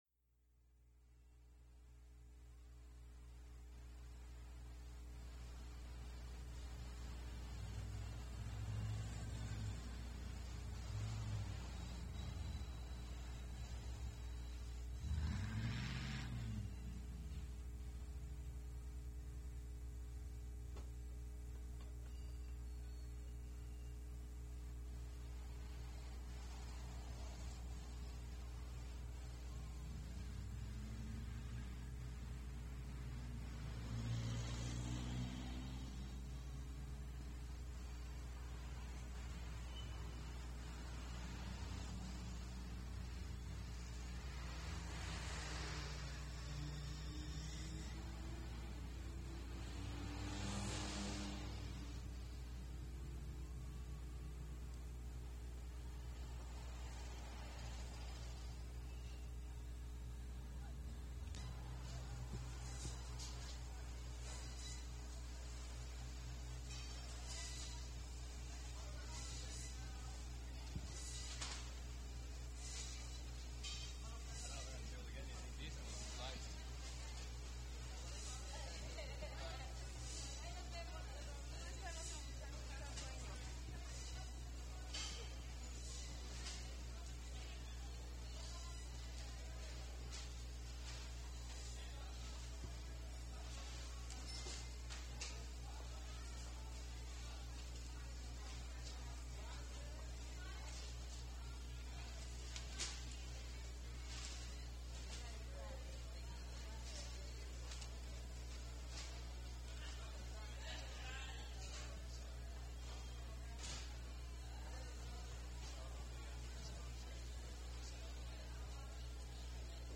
A Stereo Walking Tour Of Downtown L.A. - Summer 1977 - Past Daily Gallimaufry
So I decided it would be great to hook up two microphones, fastened to a makeshift tripod, placed 45 degrees apart and go to downtown L.A. to wander around on a typical Saturday, recording things a typical Saturday in a city did. They called it the “dummy head method” of recording; a process that give everything a three-dimensional feel to it, but only if you wore headphones to truly appreciate it. On this particular day there was a celebration – almost 50 years later, I forget which one. But it was loud and there was a lot going on.